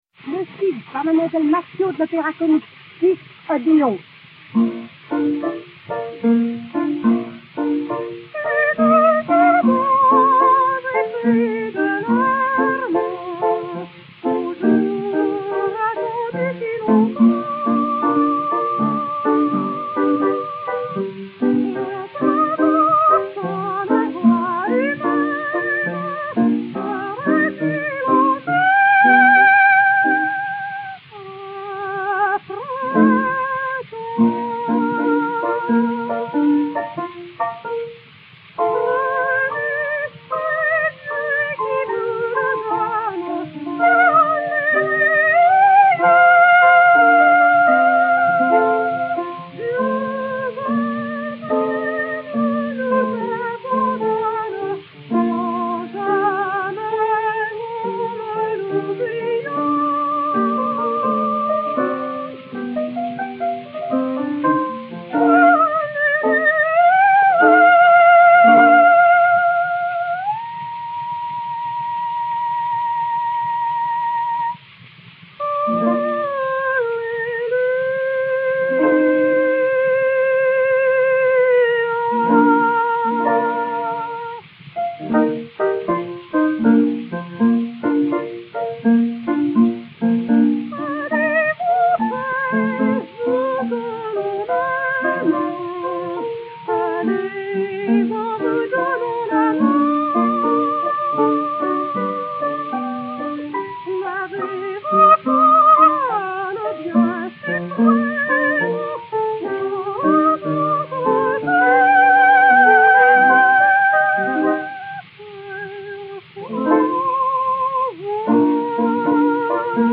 et Orchestre
XP 98, enr. à Paris en 1904